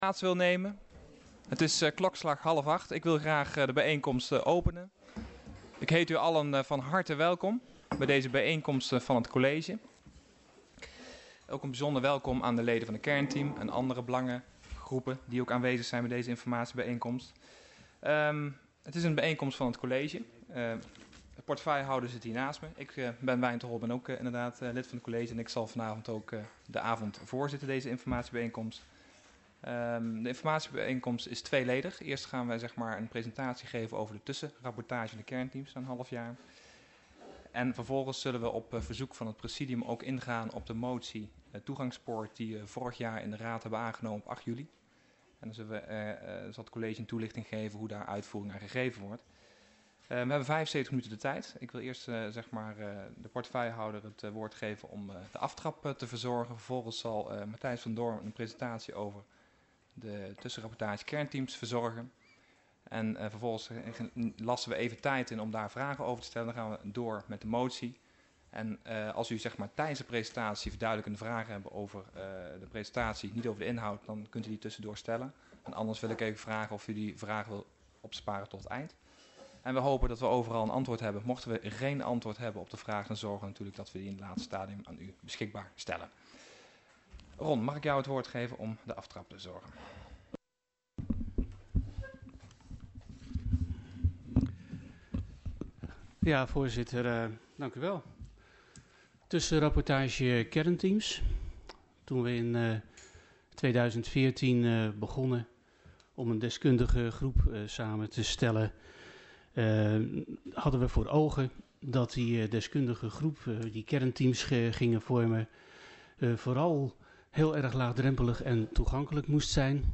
Locatie Hal, gemeentehuis Elst Toelichting Informatiebijeenkomst van College: Tussenrapportage Kernteams Agenda documenten 15-07-14 Opname hal inzake Informatiebijeenkomst van College Tussenrapportage Kernteams.MP3 32 MB